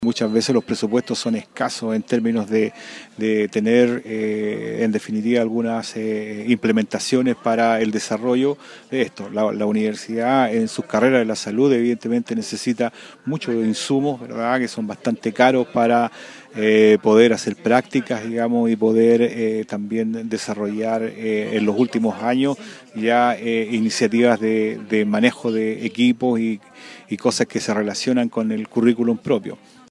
Pasado el mediodía de este jueves, se llevó a cabo la ceremonia de entrega de una importante donación de insumos médicos, proyecto financiado en conjunto con la Sociedad Pucobre, la Compañía Minera Contractual Carola y la Universidad de Atacama.